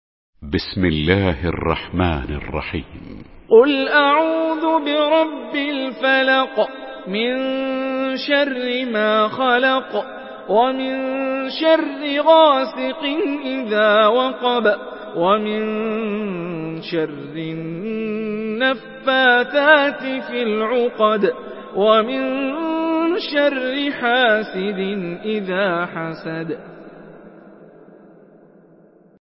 Surah Felak MP3 by Hani Rifai in Hafs An Asim narration.
Murattal